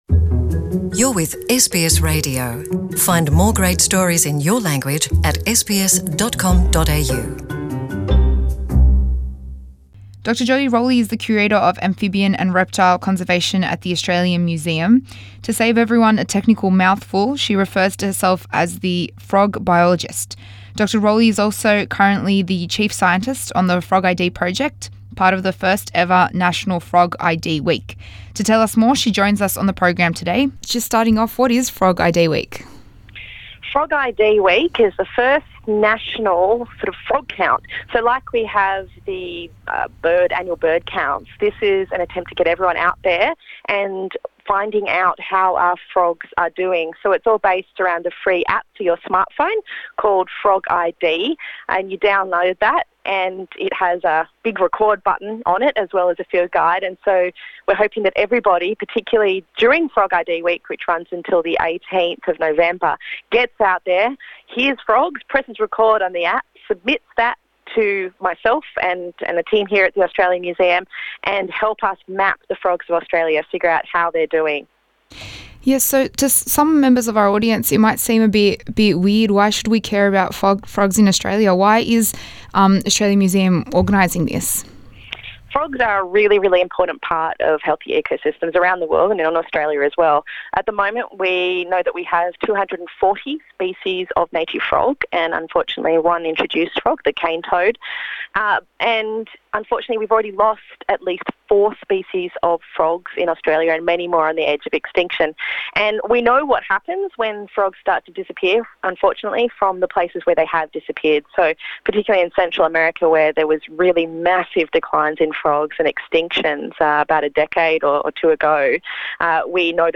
To tell us more she joins us on the program.